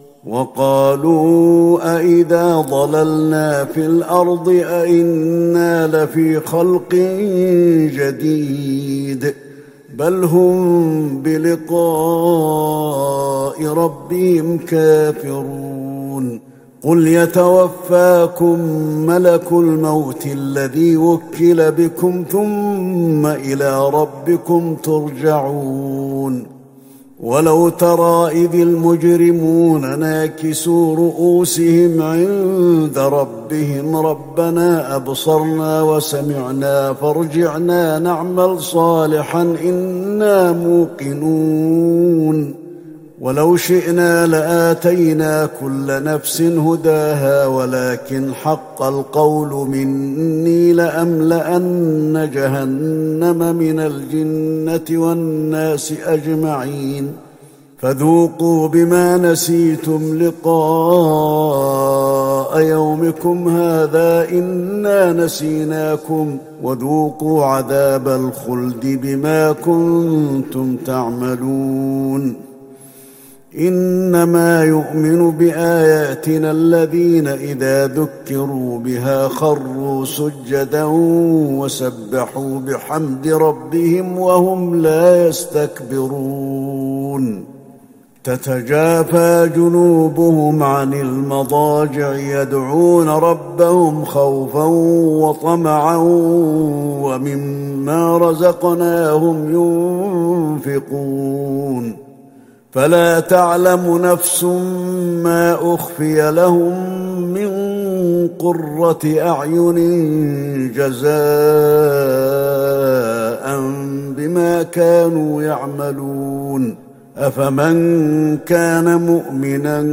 تهجد ٢٤ رمضان ١٤٤١هـ من سورة السجدة { ١٠-٣٠ } والأحزاب { ١-٨ } > تراويح الحرم النبوي عام 1441 🕌 > التراويح - تلاوات الحرمين